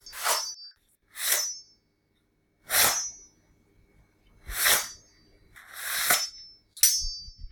Machette drawn from a sheath
blade cling cylinder ding draw drawing knife Machette sound effect free sound royalty free Sound Effects